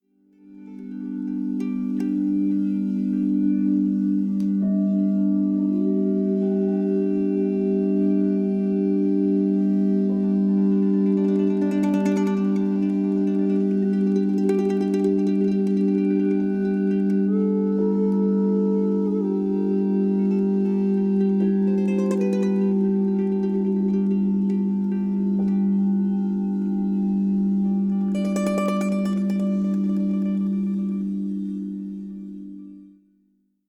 This album is an acoustic offering for them.